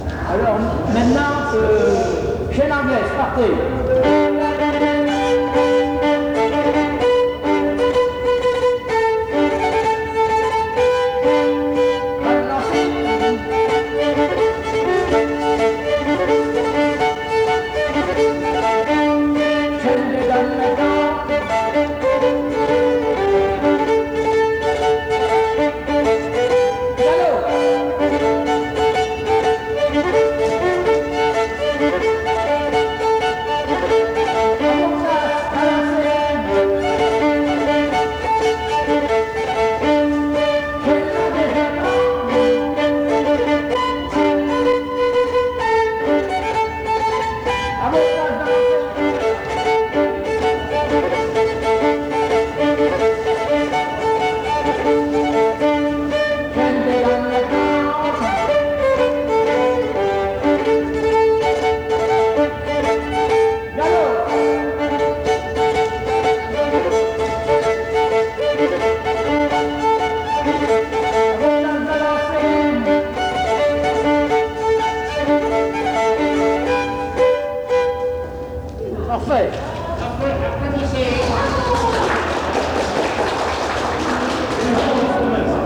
danse : quadrille : chaîne anglaise
Pièce musicale inédite